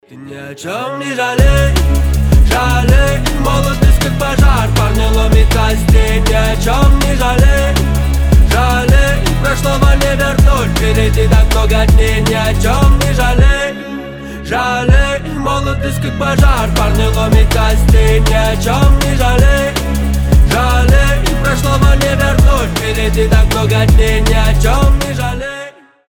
вдохновляющие
воодушевляющие
драм энд бейс